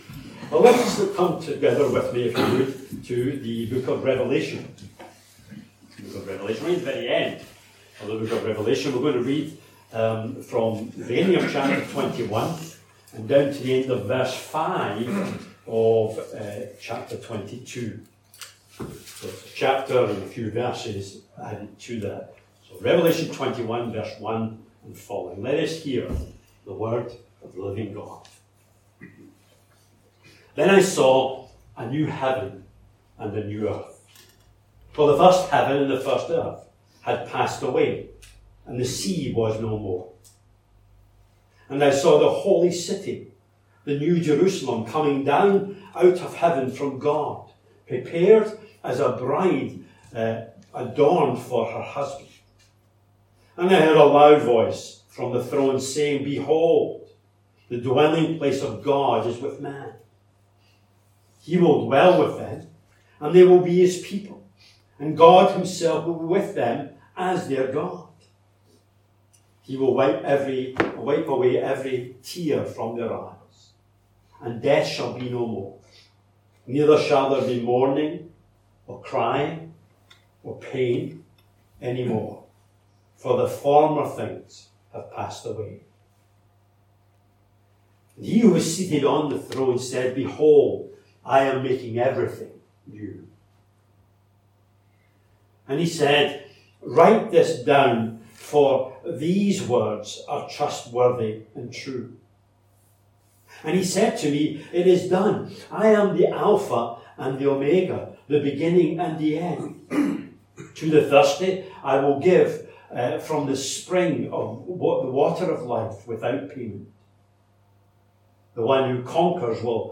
A link to the video recording of the 6:00pm service and an audio recording of the sermon.